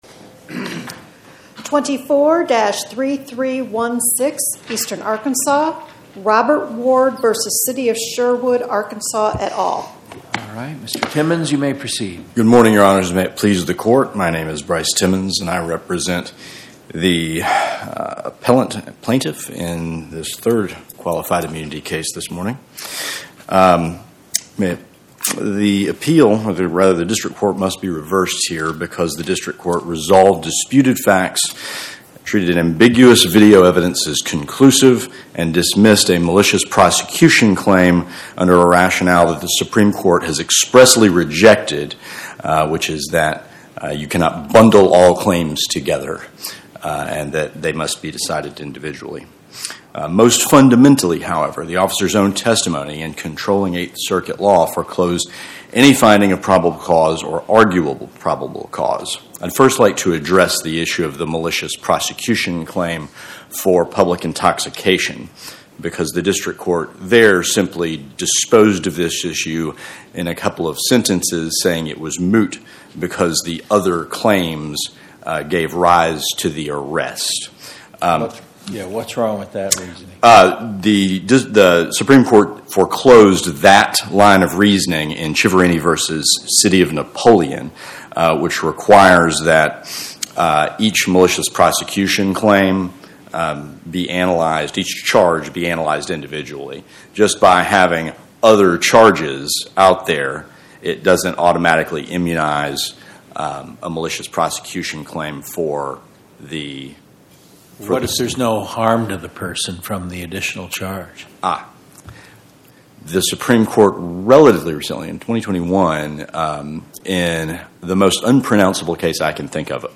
Oral argument argued before the Eighth Circuit U.S. Court of Appeals on or about 11/19/2025